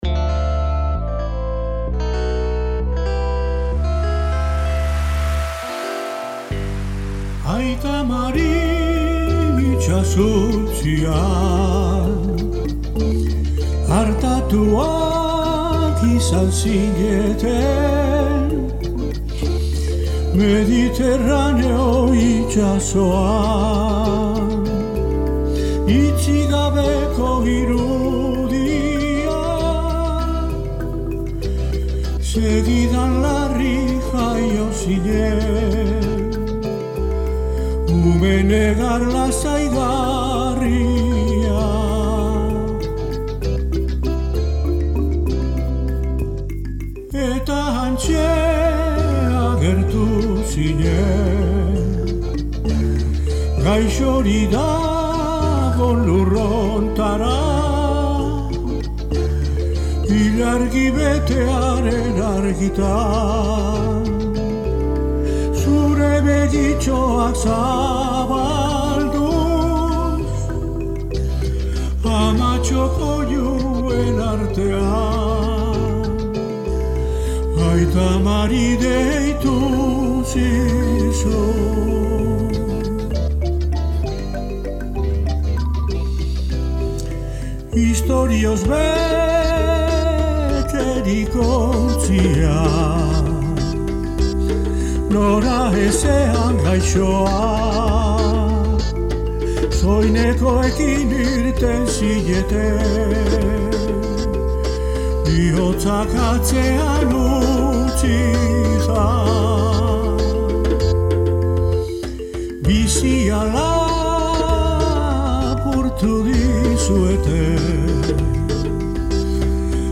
etxeko estudioan grabatu zuen kantua